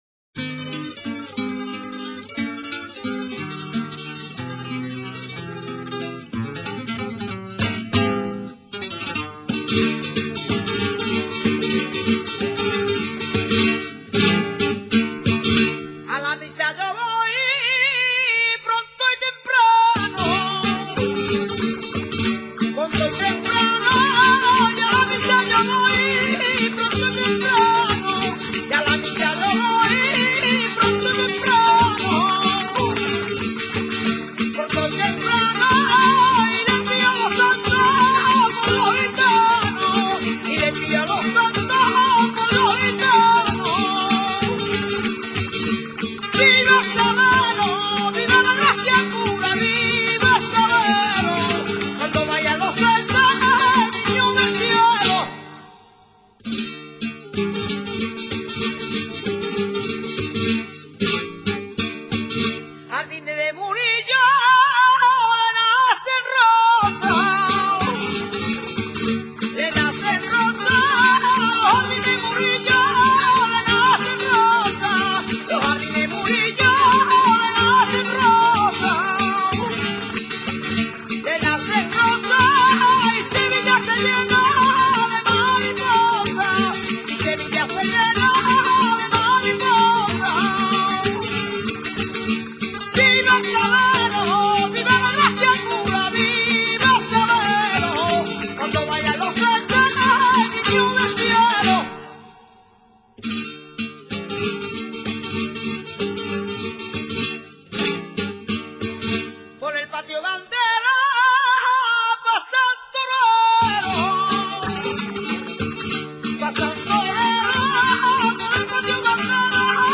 Es el arquetipo de la canci�n folkl�rica aflamencada y tuvo siempre la finalidad de acompa�ar el baile del mismo nombre. Se caracteriza por su gracia, su viveza, su �gil dinamismo y su flexibilidad. Su toque de guitarra se hace en cualquier tono.
sevillana.mp3